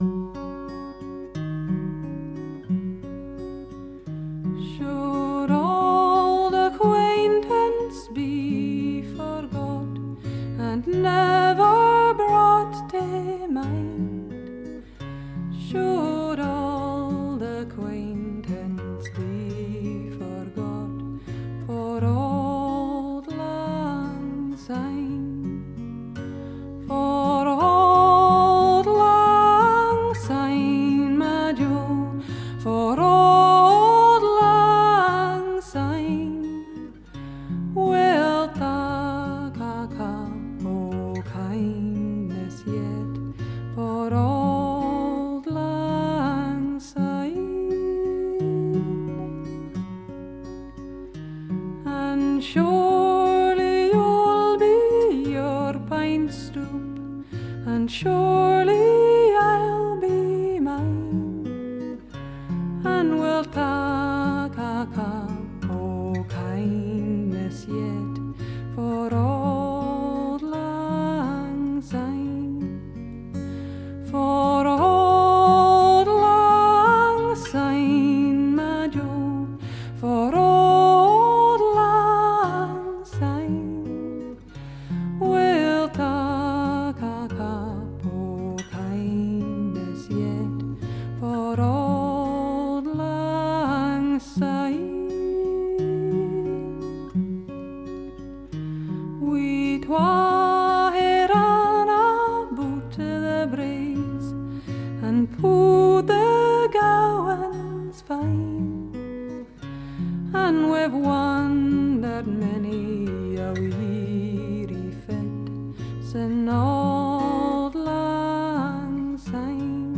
语言／风格：英语／部分古苏格兰语，Celtic